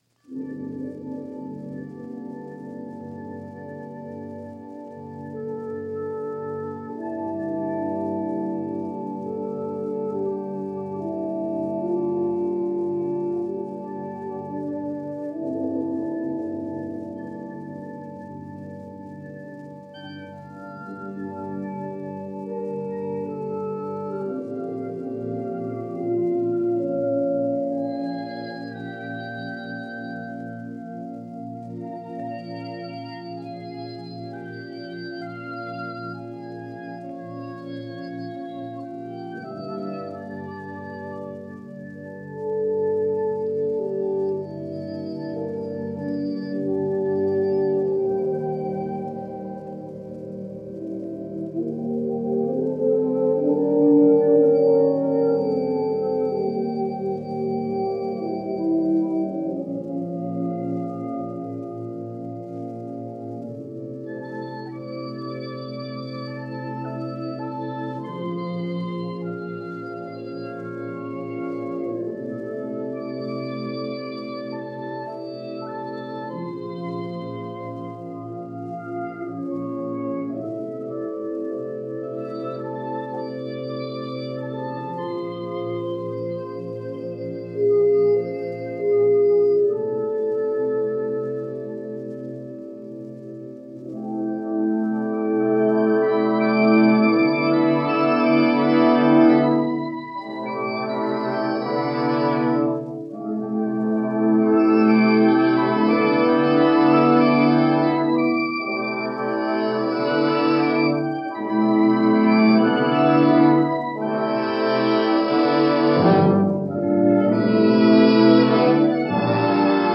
radio performance